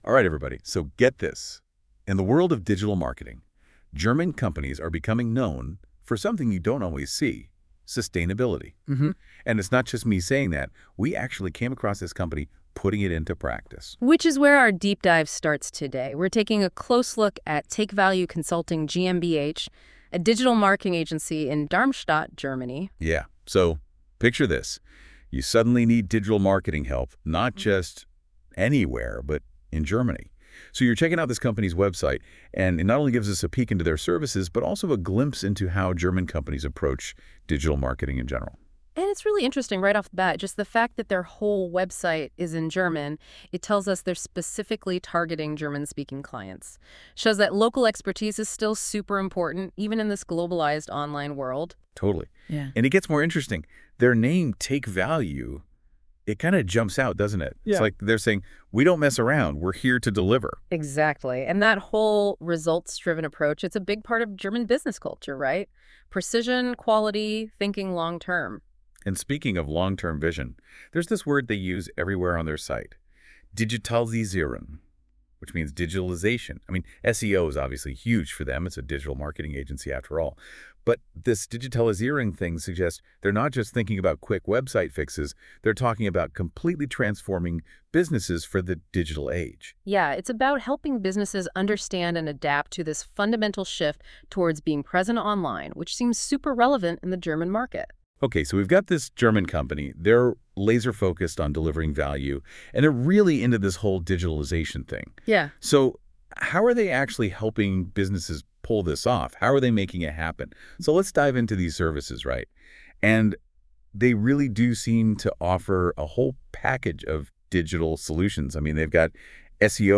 Die Funktion erzeugt eine lebhafte Diskussion zwischen zwei KI-Hosts – einer weiblichen und einer männlichen Stimme.
Die Stimmen wirken lebendig und wechseln sich fließend ab, was das Zuhören angenehm gestaltet. Obwohl man erkennen kann, dass es sich um KI-generierte Stimmen handelt, ist die Qualität beeindruckend.
Allerdings fiel auf, dass die Gespräche manchmal eher wie vorgelesen klingen und weniger wie eine echte, spontane Unterhaltung.
• Tonfall und Themenwahl: Die KI-Hosts verwenden einen eher lockeren Ton, der bei ernsten oder sensiblen Themen unpassend wirken kann.
Das Ergebnis ist erstaunlich: Aus unseren Inhalten entstand ein lebhaftes Gespräch zwischen den KI-Hosts, das unsere Themen auf interessante Weise beleuchtet.